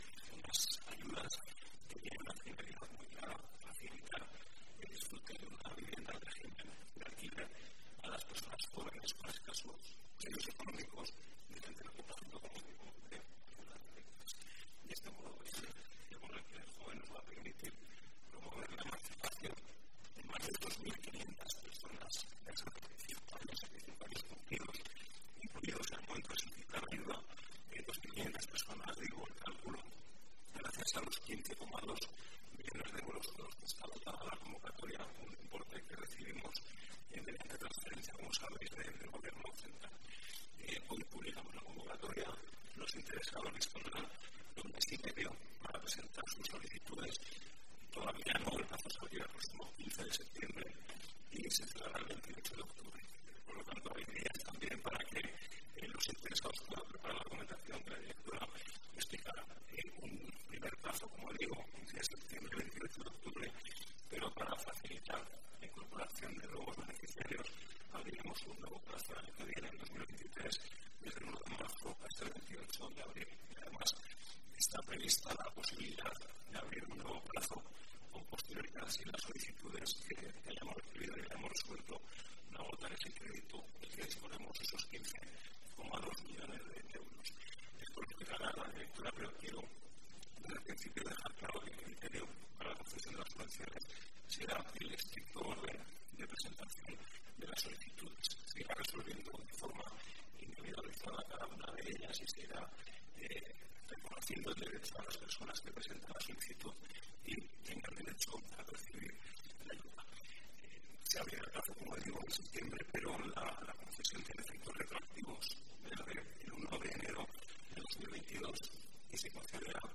El consejero de Vivienda, José Luis Soro, explica las características del Bono Joven de alquiler de vivienda.